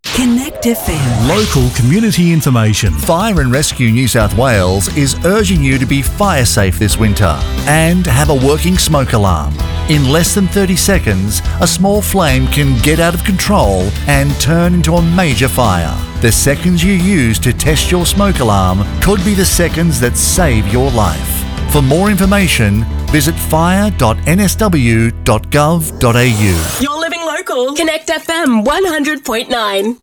I have a warm, friendly, professional and versatile voice, with the ability to adapt to many characters and scripts.